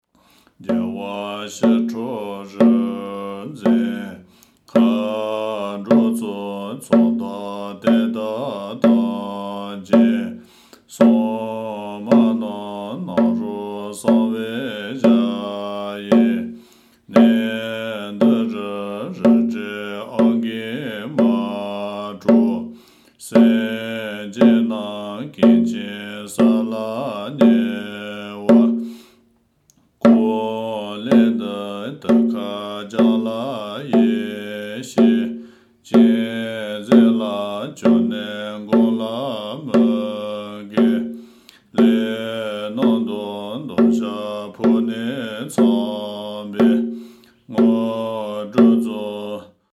རྒྱལ་བ་ཞི་ཁྲོ་རིག་འཛིན་མཁའ་འགྲོའི་ཚོགས། – (འདེབས་རིང་།)  |  deb-ring (KCS#49:  སྤྱི་བསྐང་རིན་ཆེན་གཏེར་སྤུངས་སྐད།)